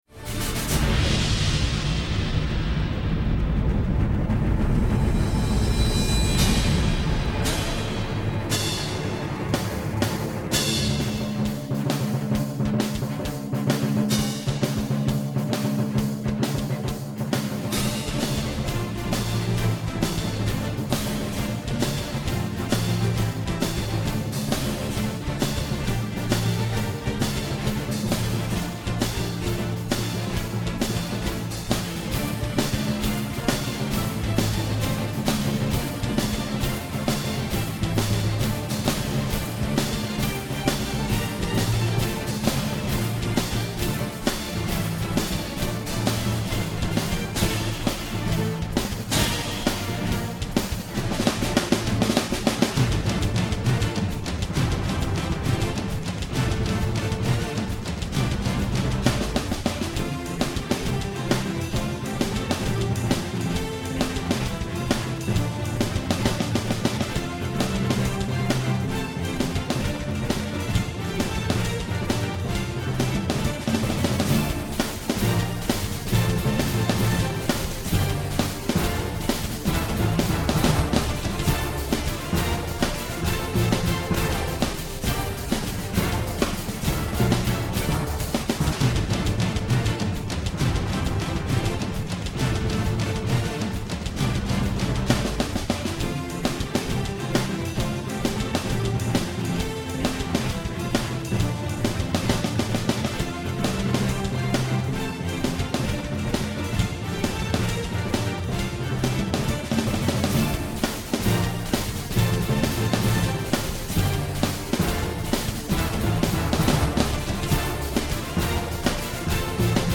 BRASS ENSEMBLE • ACCOMPAGNAMENTO BASE MP3
Trombone 1 - 2 - 3 - 4 - 5
Timpani